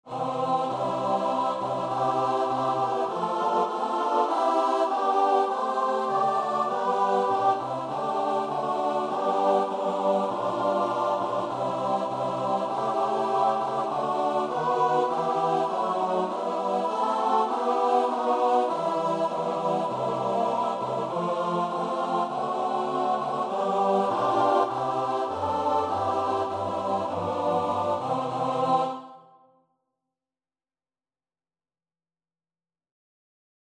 Free Sheet music for Choir (SATB)
SopranoAltoTenorBass
4/4 (View more 4/4 Music)
C major (Sounding Pitch) (View more C major Music for Choir )
Classical (View more Classical Choir Music)